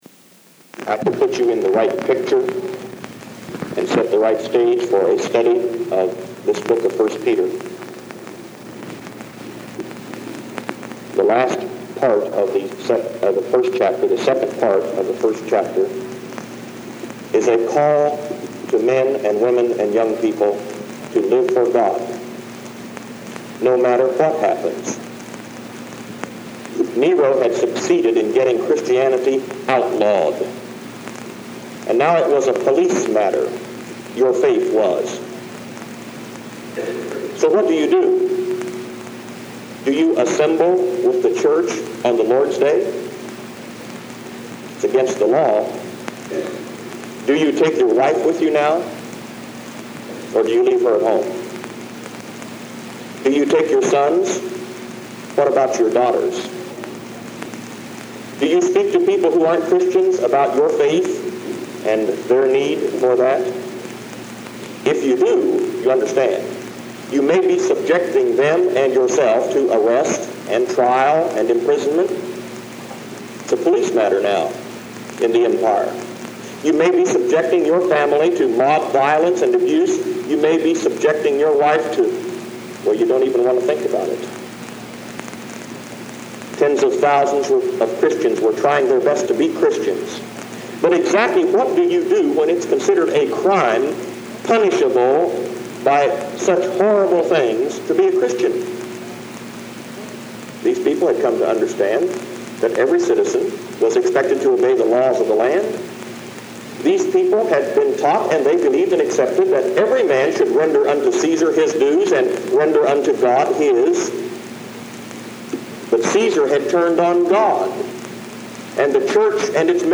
The quality of the audio on this cassette was not very good, and it seems the beginning and end of this sermon have been lost due to the deterioration of the cassette tape.